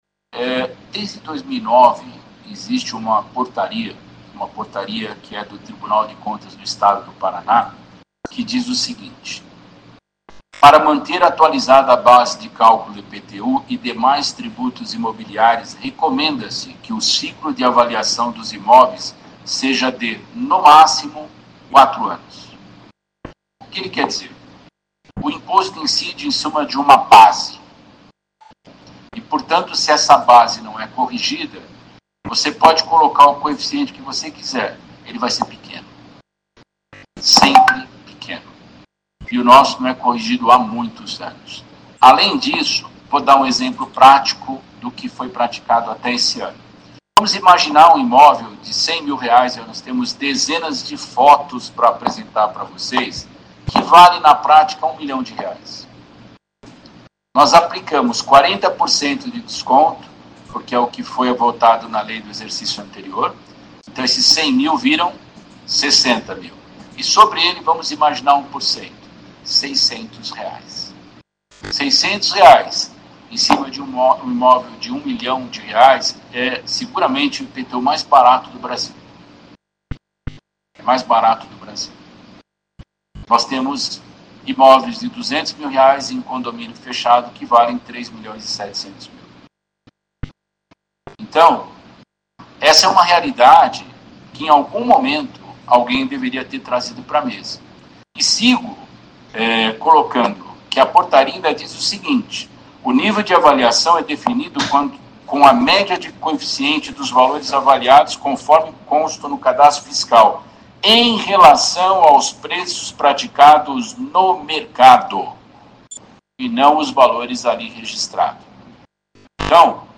Secretário de Fazenda explica alterações no IPTU 2026
Durante a prestação de contas do 2º quadrimestre de 2025, o secretário de Fazenda de Maringá, Carlos Augusto Ferreira, explicou aos vereadores uma mudança no cálculo do IPTU que trará impacto em 2026.